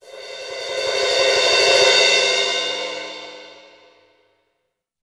Index of /90_sSampleCDs/Best Service ProSamples vol.33 - Orchestral Loops [AKAI] 1CD/Partition F/60 PA DEMO